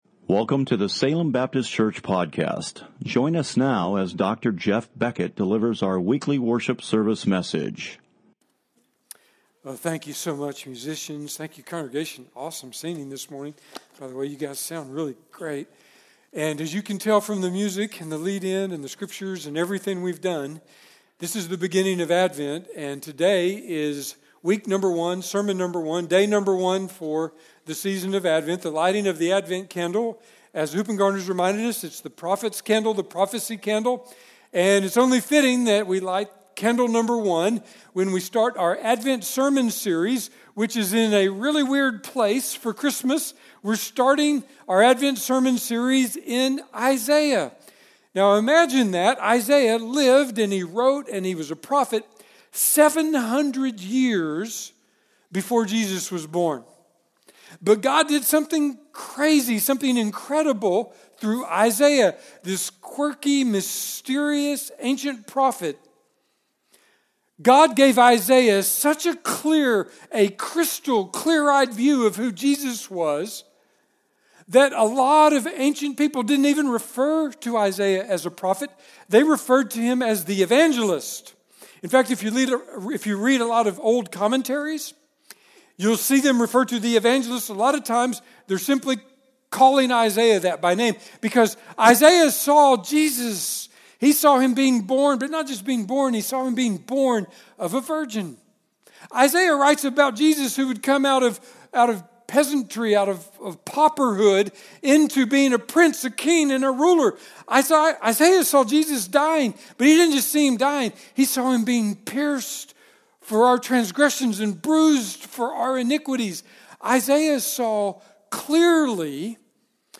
Advent Sermon – Wonderful Counselor: Isaiah 9:6, Luke 2:8 13